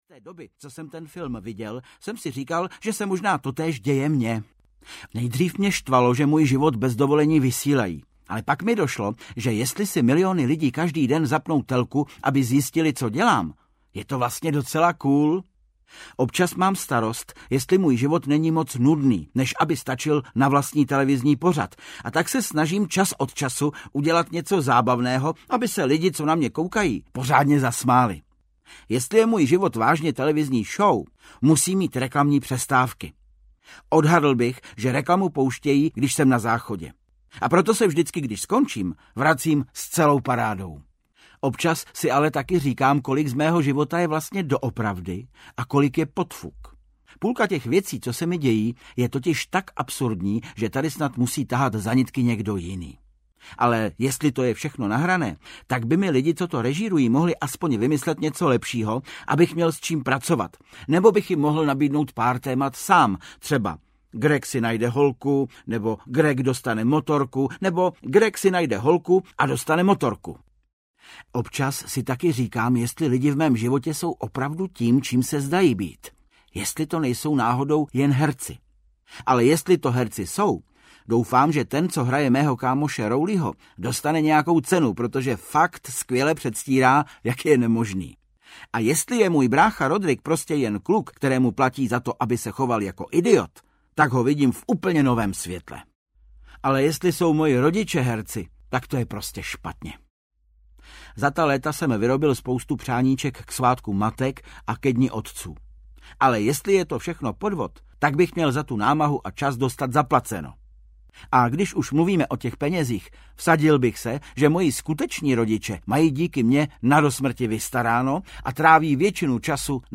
Deník malého poseroutky 11 audiokniha
Ukázka z knihy
• InterpretVáclav Kopta